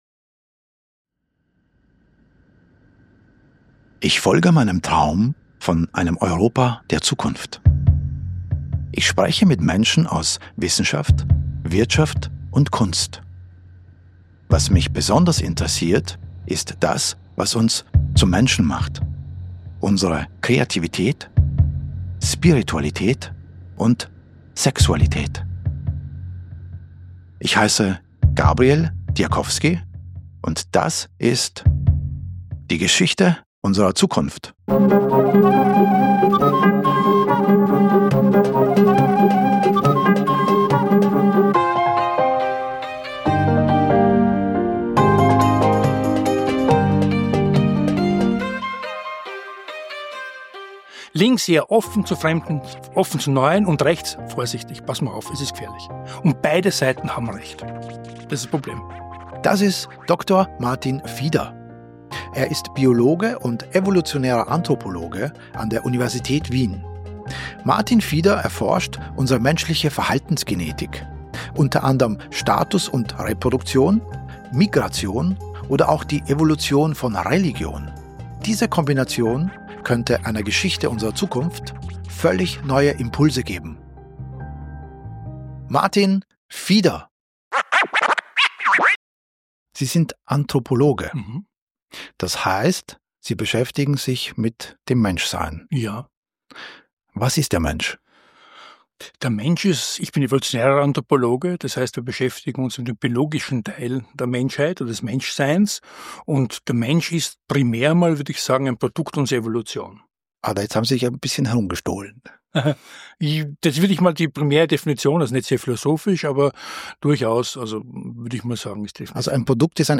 Auch wenn er zu schnell spricht: Seine Perspektive ist mitreissend!